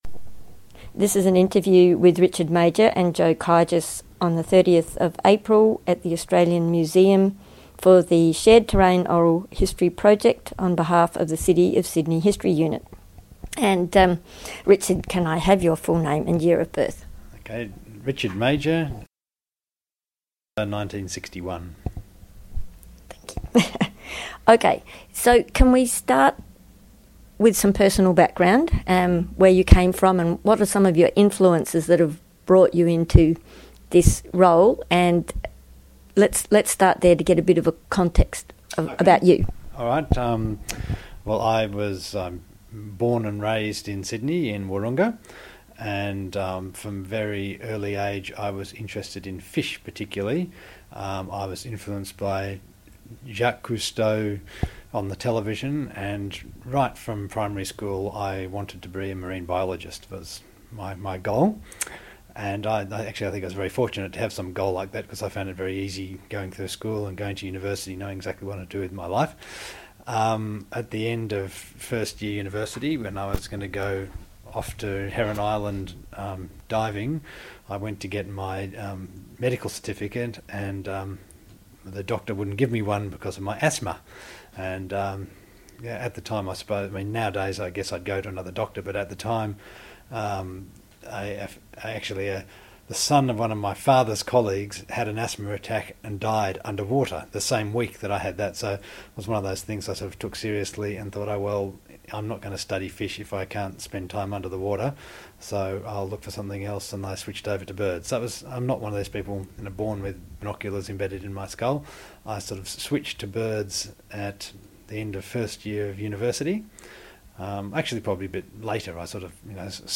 This interview is part of the City of Sydney's oral history theme: Shared Terrain